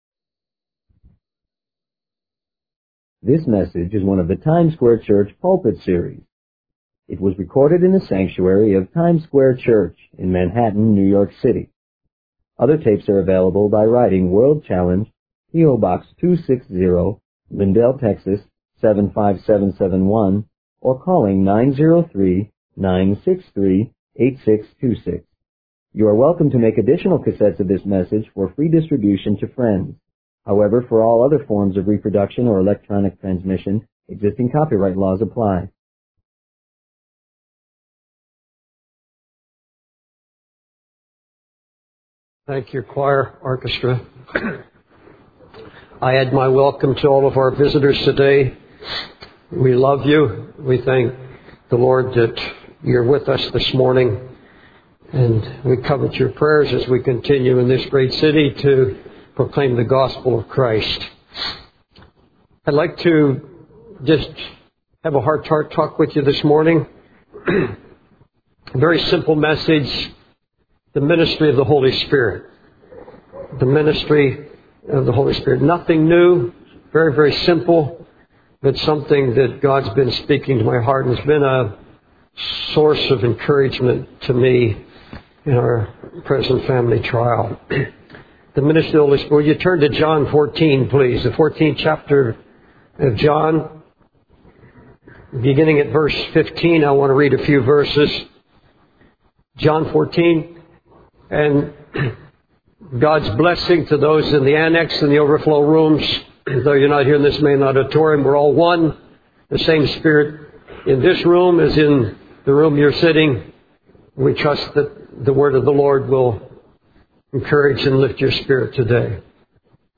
In this sermon, the speaker begins by expressing gratitude and praise to God.